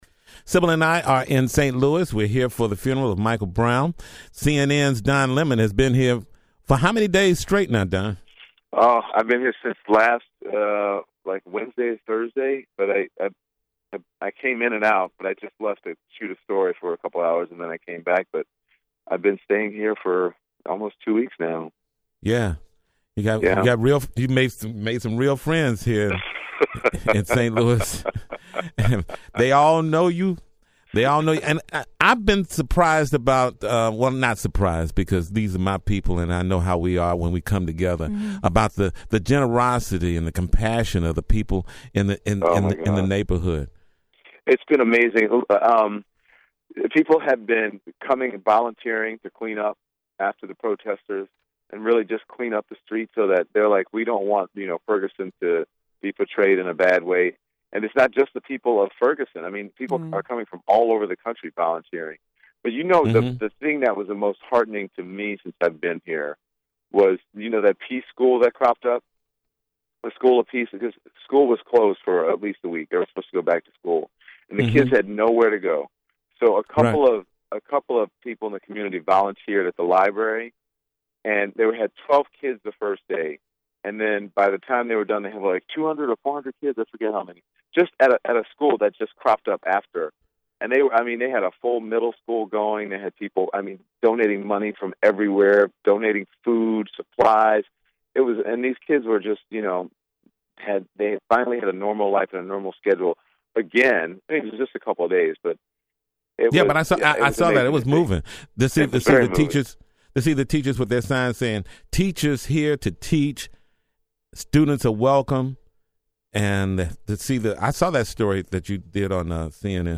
Don Lemon Talks Annual Peace Fest, Interviewing The Mothers of Michael Brown, Sean Bell & Trayvon Martin